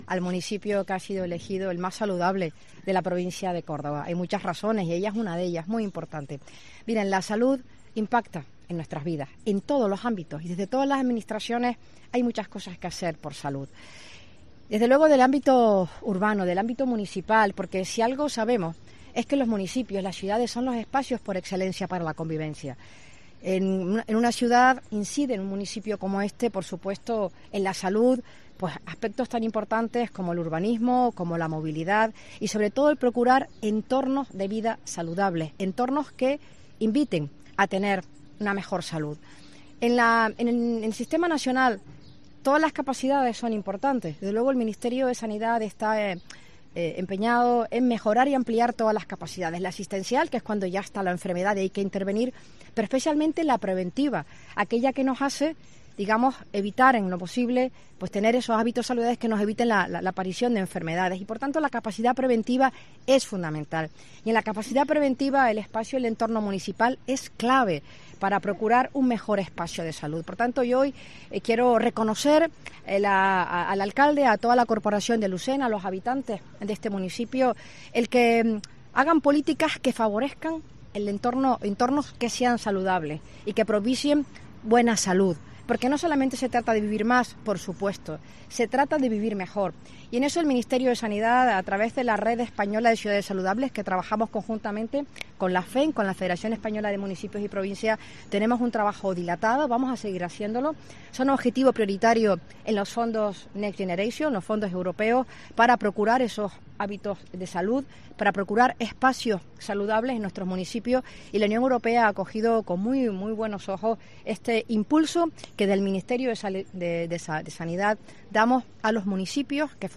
En declaraciones a los periodistas en Lucena (Córdoba), junto al alcalde, Juan Pérez, y la subdelegada del Gobierno, Rafaela Valenzuela, tras un encuentro con colectivos, la ministra ha explicado que el fin es "llevar la medicina de vanguardia allí donde los hospitales son punteros, donde hay profesionales sanitarios punteros, que han hecho un trabajo excepcional y todo el Sistema Nacional de Salud lo reconoce".